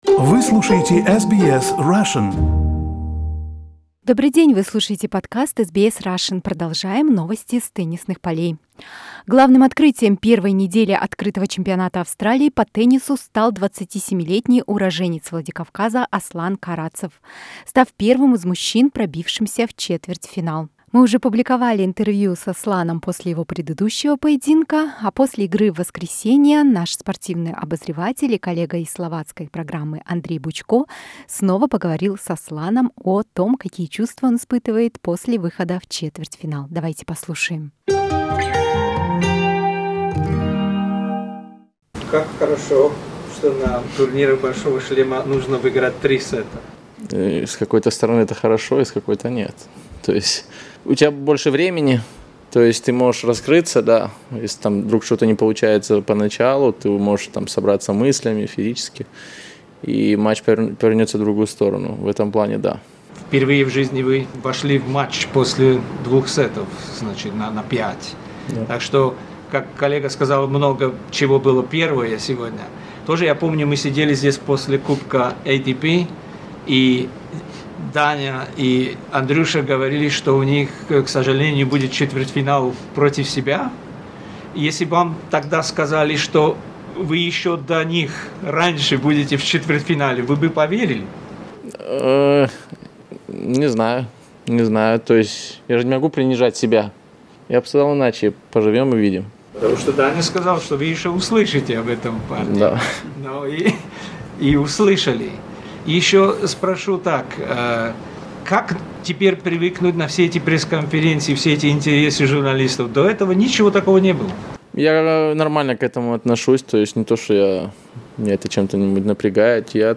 Interview with a Russian tennis player Aslan Karatsev at Australian Open 2021.